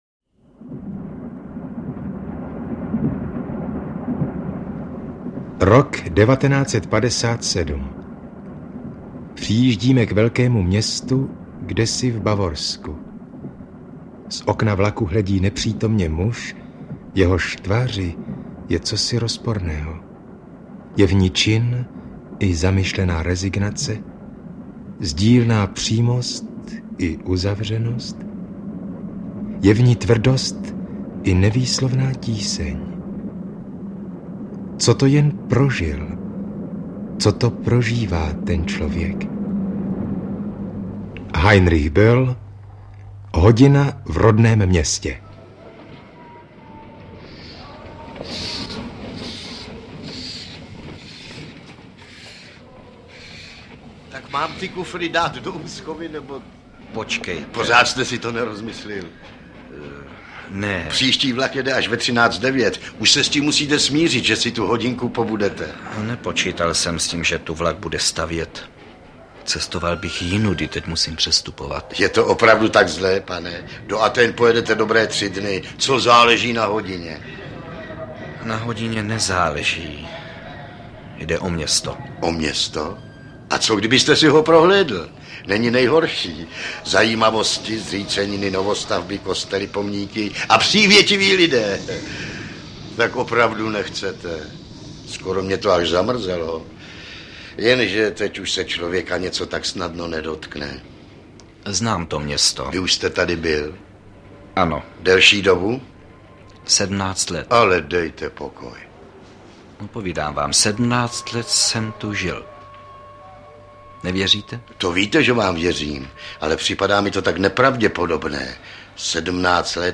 Jde o dramatizaci z roku 1962
AudioKniha ke stažení, 3 x mp3, délka 50 min., velikost 45,8 MB, česky